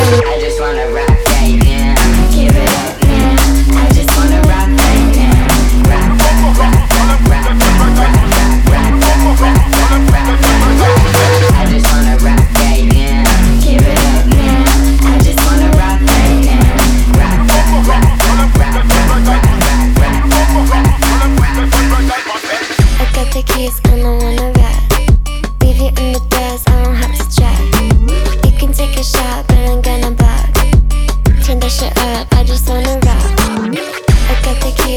2025-07-22 Жанр: Танцевальные Длительность